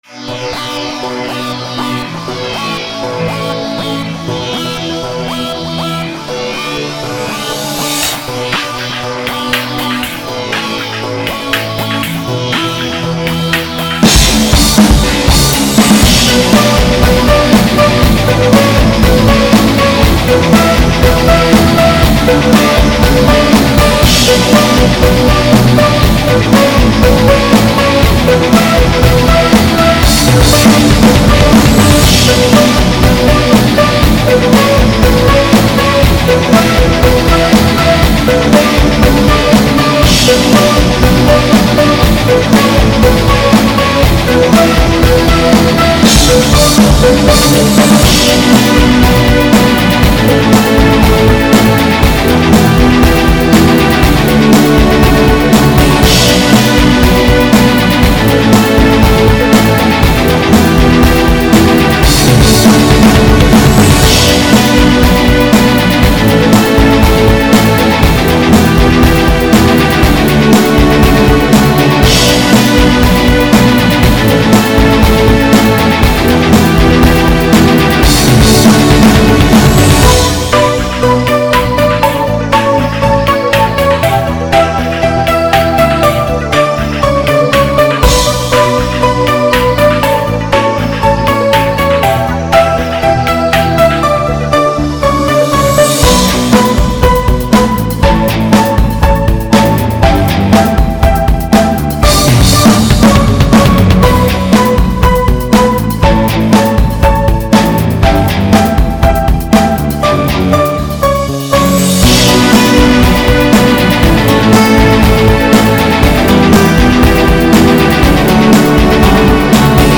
естественно тяжелый